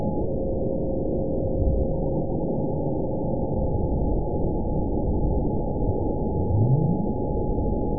event 917802 date 04/16/23 time 23:45:08 GMT (2 years ago) score 9.42 location TSS-AB04 detected by nrw target species NRW annotations +NRW Spectrogram: Frequency (kHz) vs. Time (s) audio not available .wav